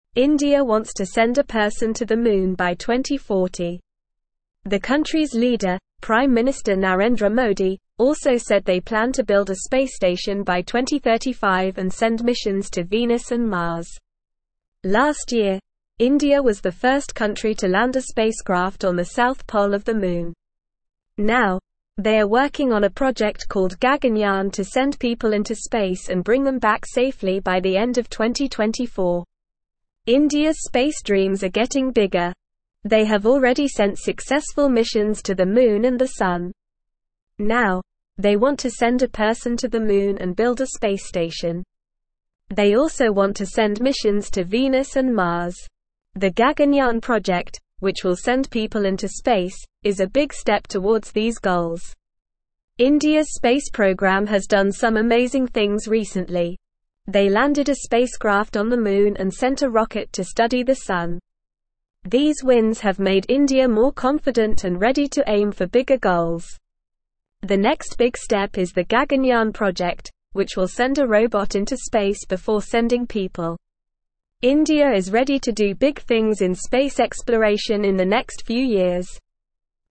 Normal
English-Newsroom-Lower-Intermediate-NORMAL-Reading-Indias-Big-Space-Dreams-Moon-Sun-and-More.mp3